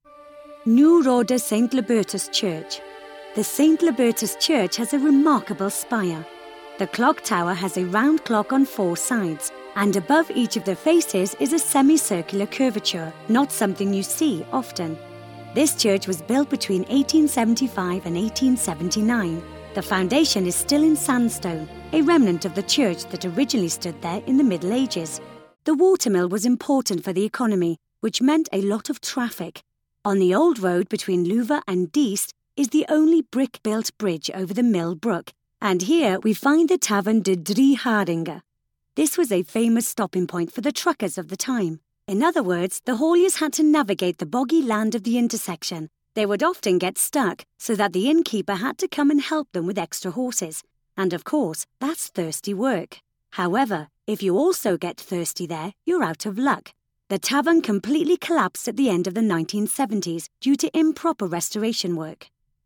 I deliver the perfect blend of British Neutral tones with a splash of smoothness; oodles of charm, a pinch of the natural and believable and you have what my client's consistently say is "The Real Deal".
british english
documentary